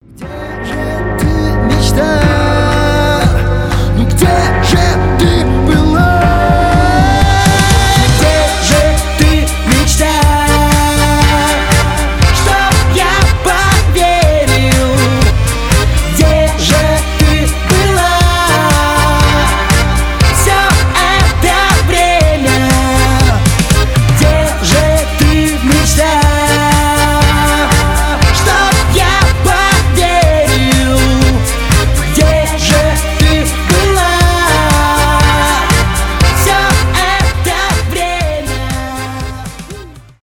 поп , рок